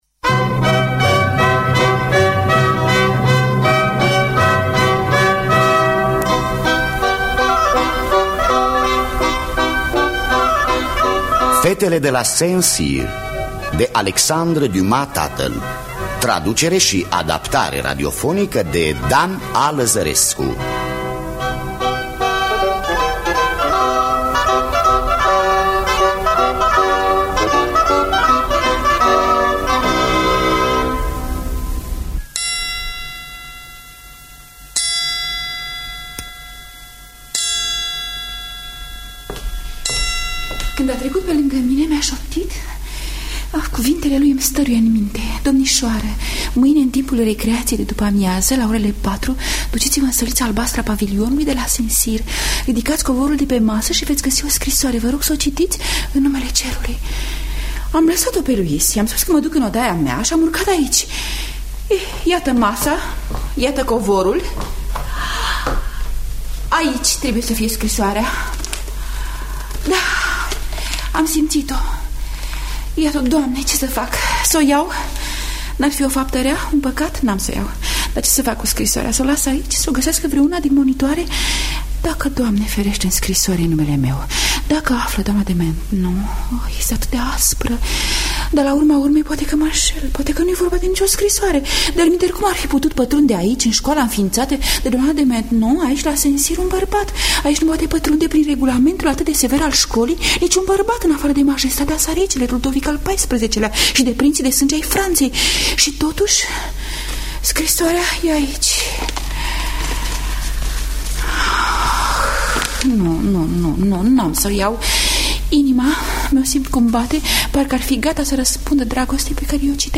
Traducere şi adaptare radiofonică